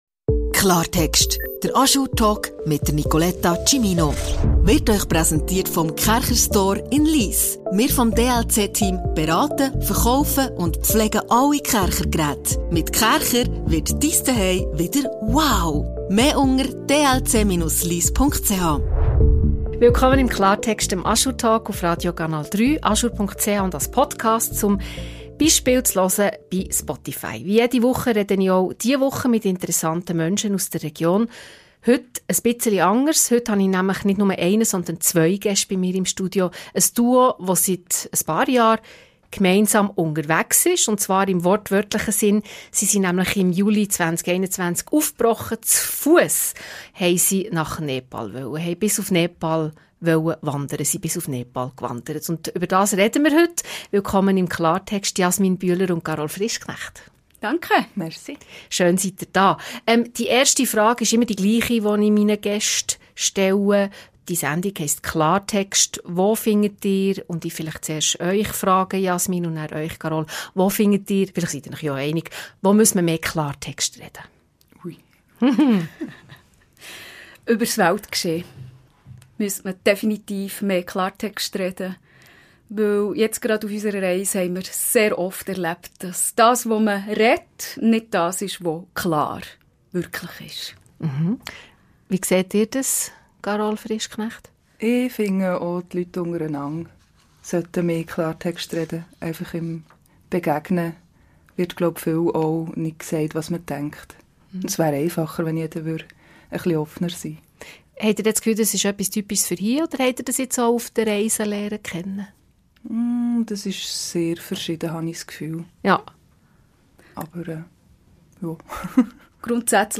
Doch vor allem prägte sie die Begegnung mit Menschen: unglaubliche Gastfreundschaft, spontane Hilfe und kleine Gesten, die den Weg leichter machten. Im Gespräch erzählen die beiden, wie diese Reise ihr Leben verändert hat, warum sie heute offener auf Menschen zugehen, was sie über Vertrauen gelernt haben. Und weshalb sie ihre kleine Mokka-Maschine bis zum Himalaya getragen haben.